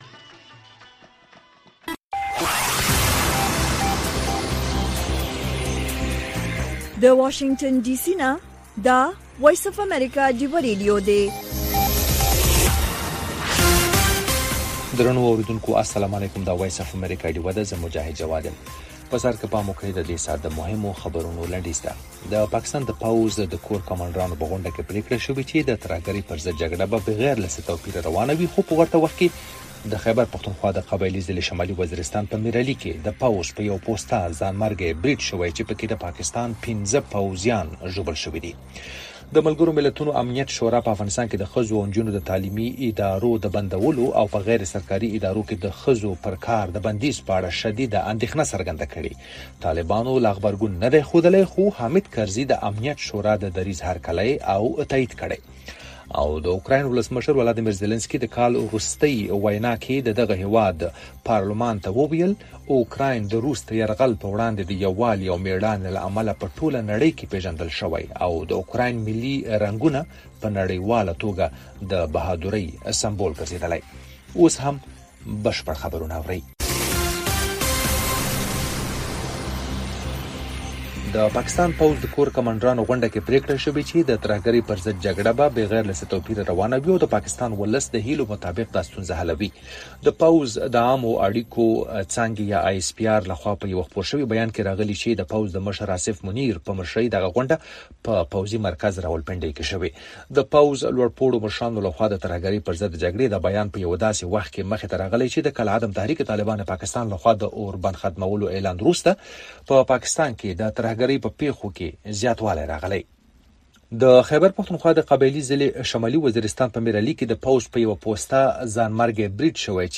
خبرونه
د وی او اې ډيوه راډيو سهرنې خبرونه چالان کړئ اؤ د ورځې د مهمو تازه خبرونو سرليکونه واورئ.